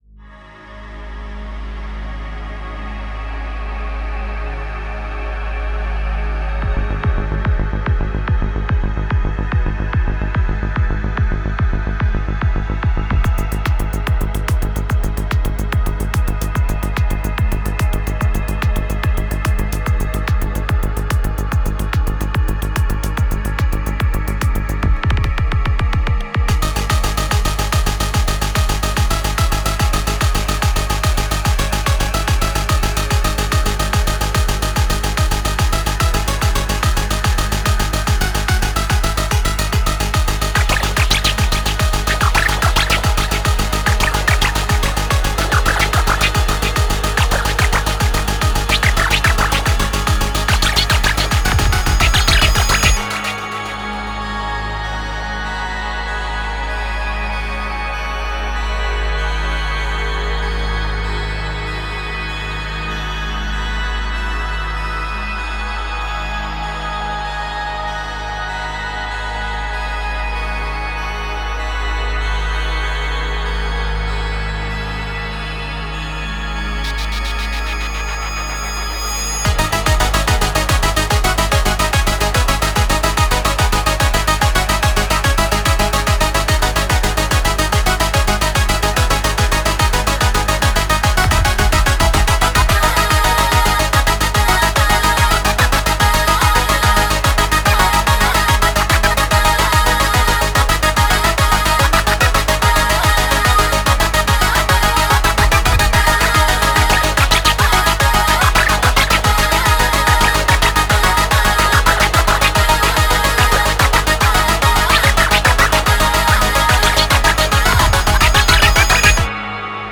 BPM48-73
Audio QualityPerfect (High Quality)
That's a synth choir.
The middle runs at 2/3 speed.
The middle runs at 5/4, I reckon.